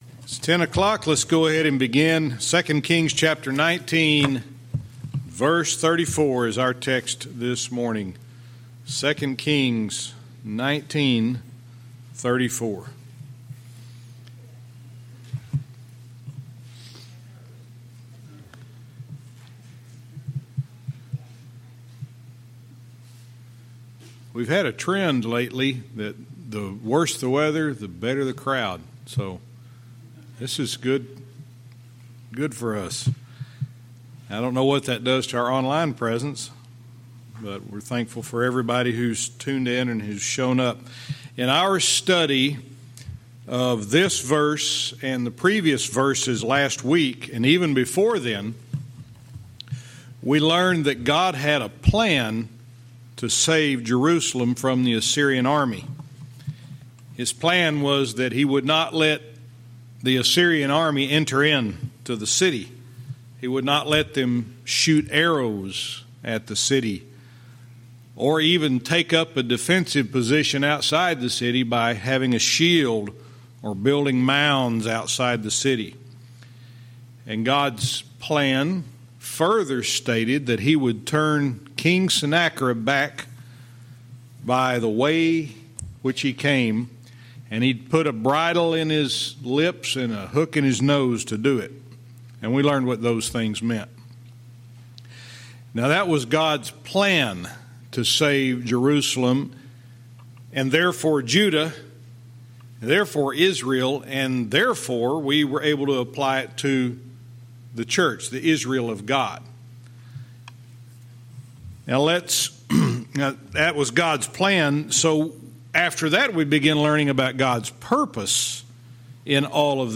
Verse by verse teaching - 2 Kings 19:34(cont)-20:1